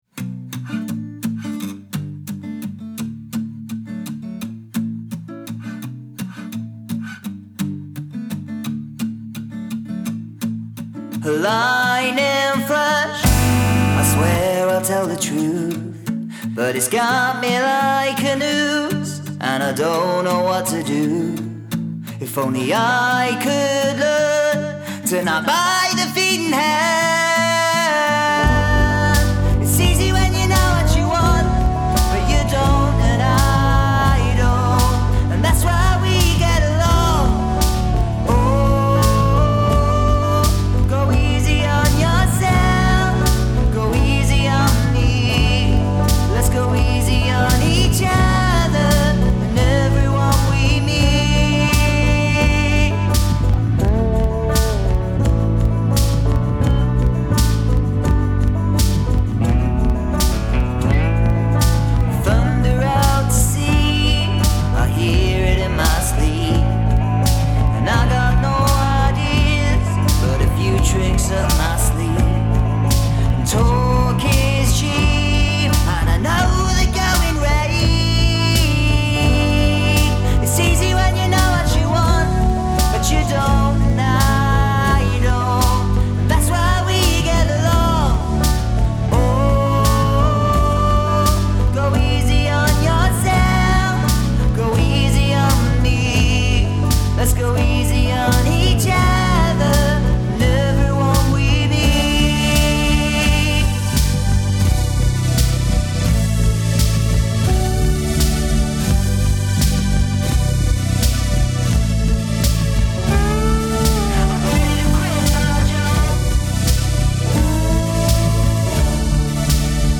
Male Vocal, Acoustic Lap Steel, Lap Steel, Synth, Drums